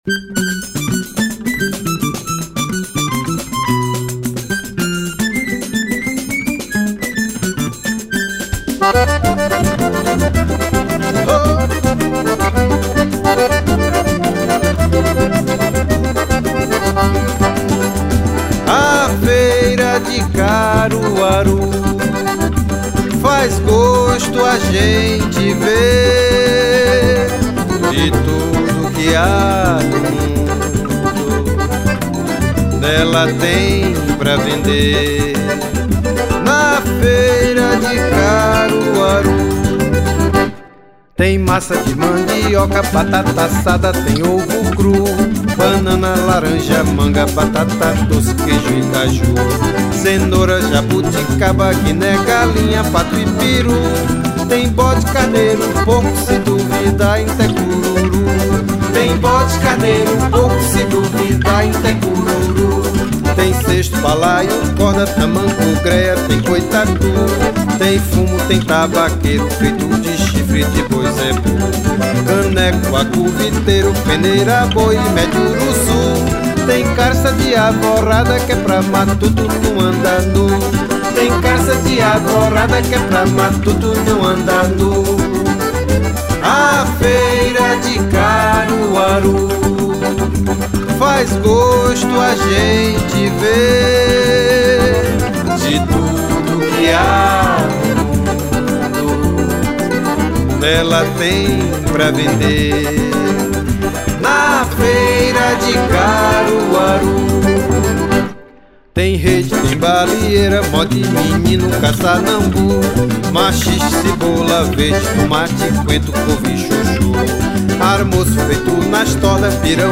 1446   03:17:00   Faixa:     Forró